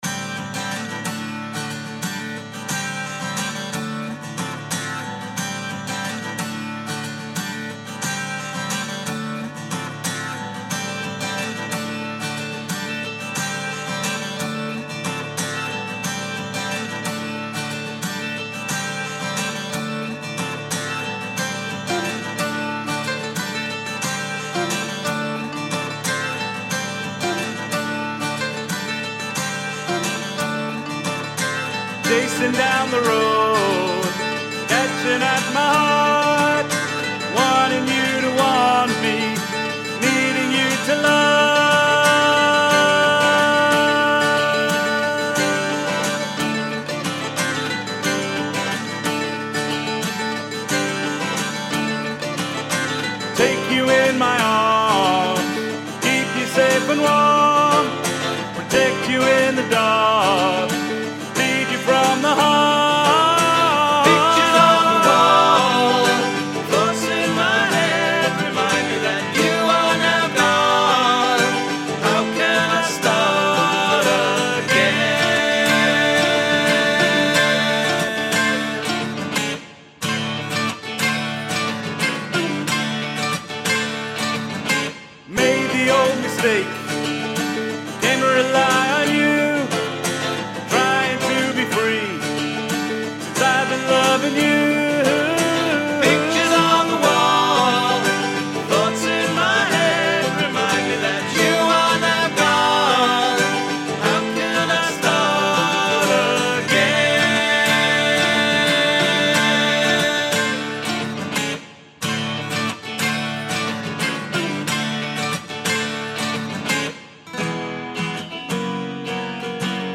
Previously unreleased studio tracks